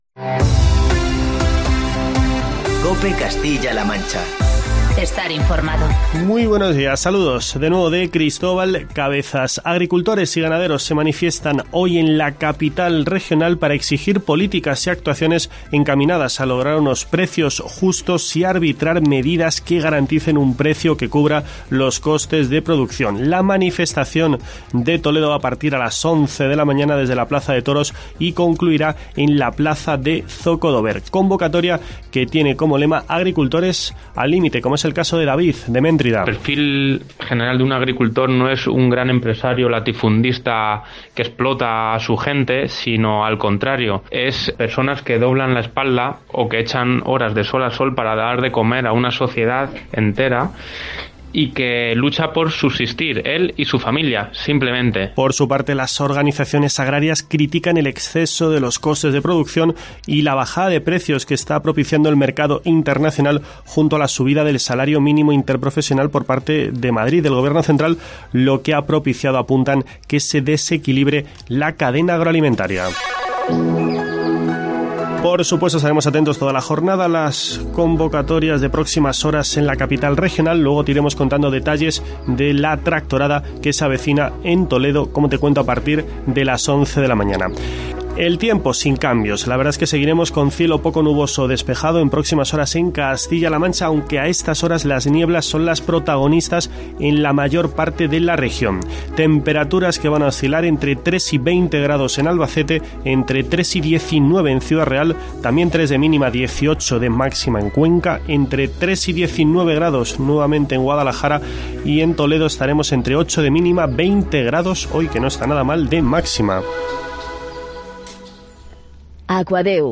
Escucha en la parte superior de esta noticia el informativo matinal de COPE Castilla-La Mancha y COPE Toledo.